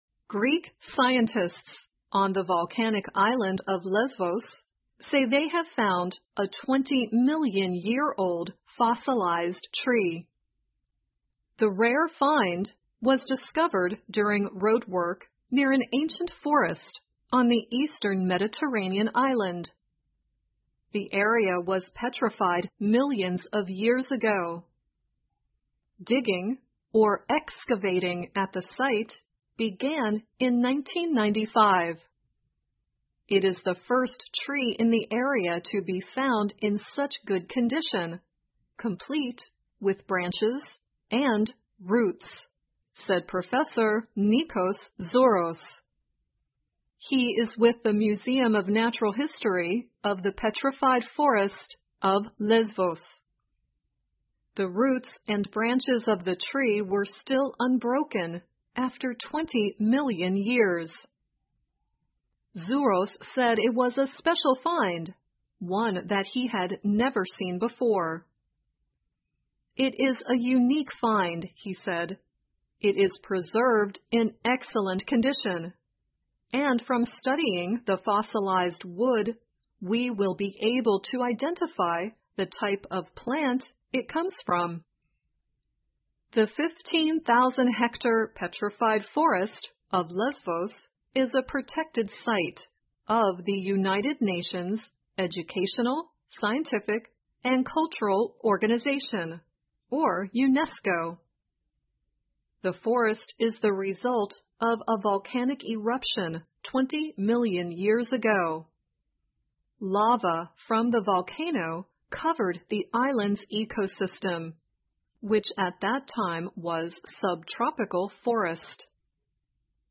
VOA慢速英语2021--希腊发现2000万年前化石树 听力文件下载—在线英语听力室